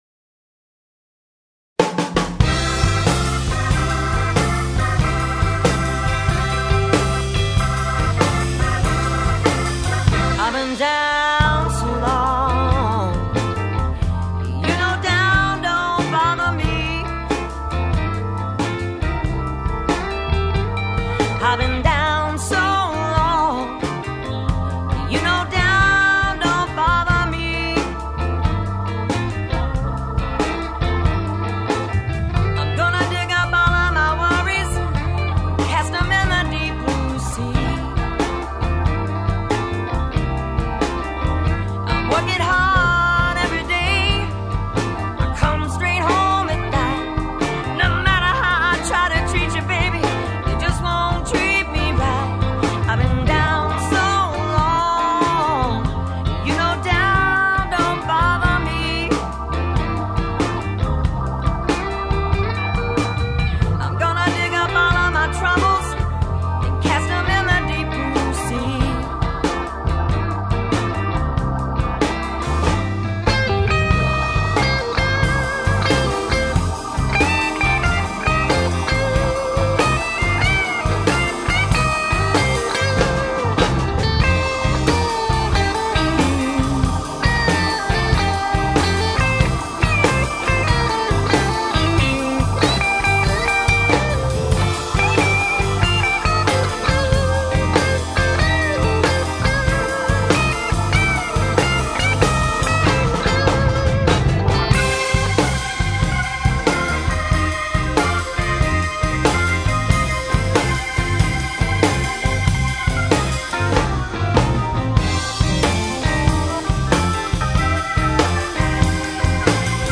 Guerilla Recording with 16 Bit ADATs
I tracked this in a guy's basement with two 16 bit ADATs. It's a live take with the guitar lead the only dub.